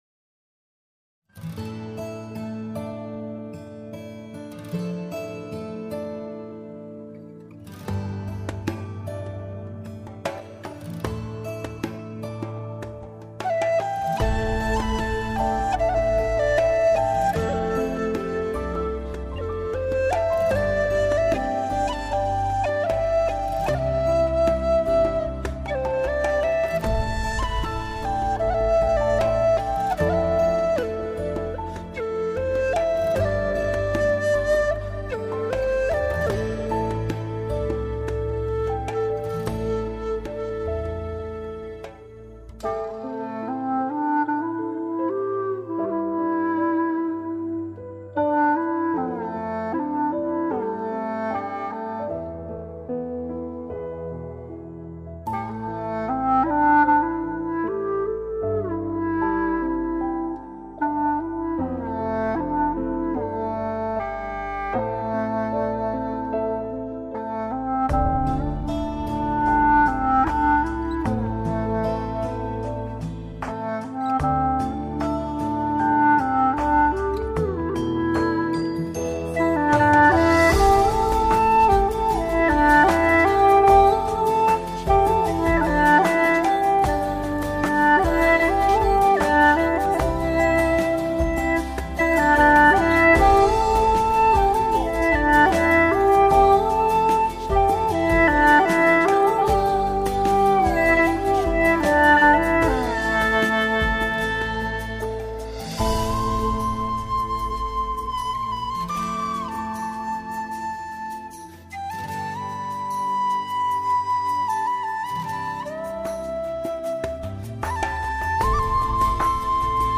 调式 : C 曲类 : 古风